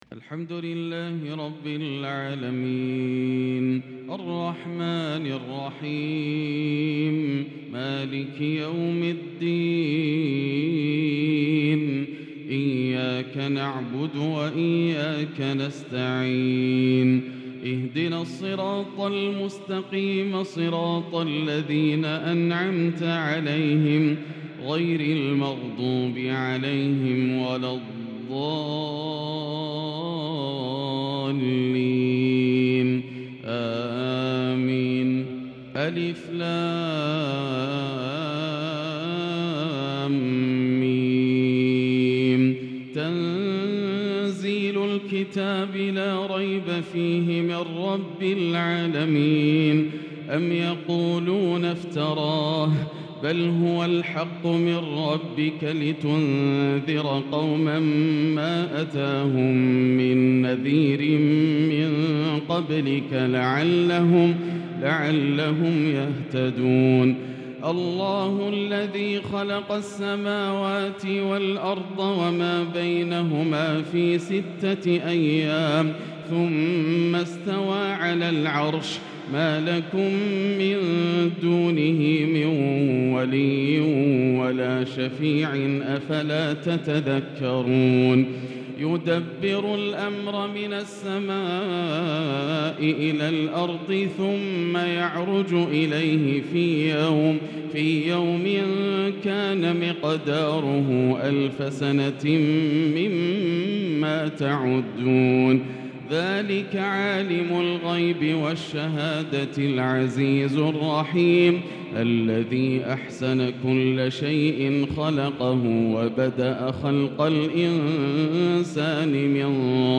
فجر الجمعة 1-8-1443هـ سورتي السجدة و الإنسان | Fajr prayer Surat Alsajdah and Alinsan 4-3-2022 > 1443 🕋 > الفروض - تلاوات الحرمين